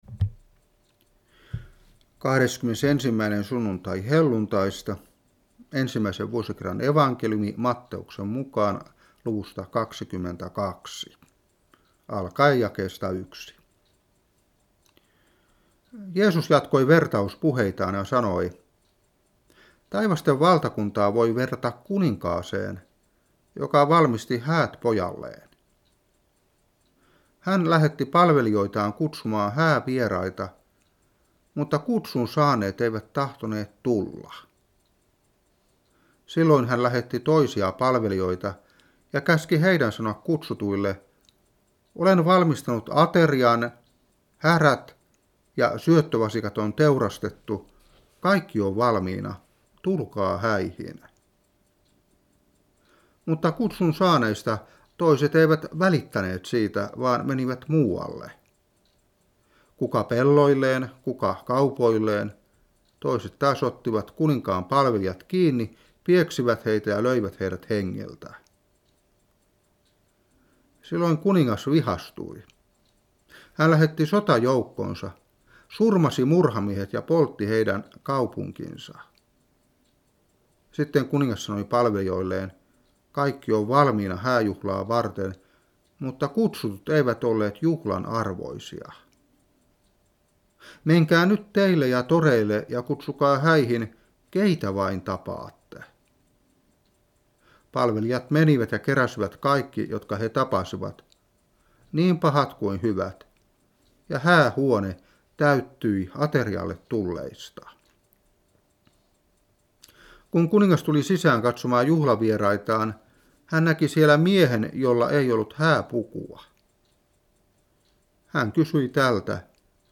Saarna 2016-10.